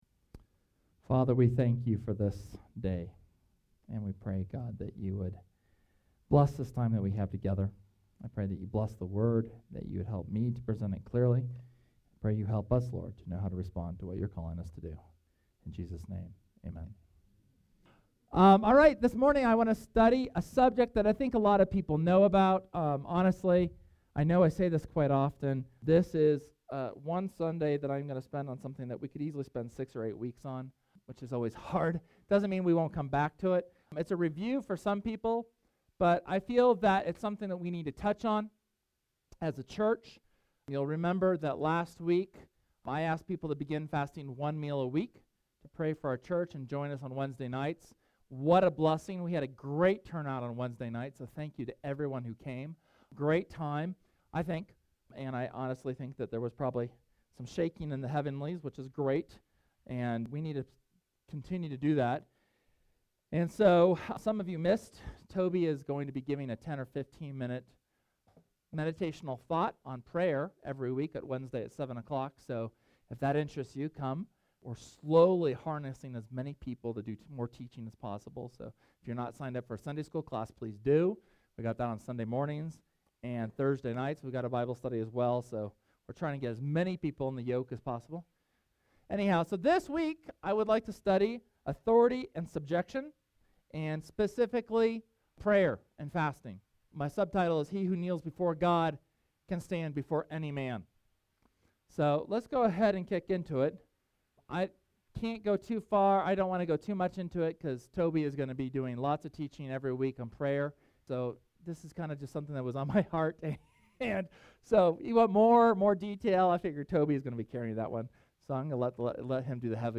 Sermon from Sunday, January 13th on the our authority in Christ through prayer and the subjection of our flesh through fasting.